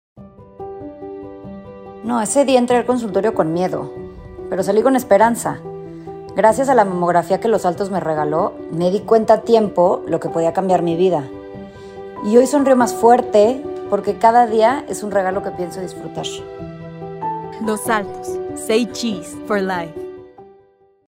Historias reales de mujeres reales
Los nombres y voces utilizados son ficticios, con el objetivo de transmitir mensajes universales de prevención y esperanza.
testimonial-1-v2.mp3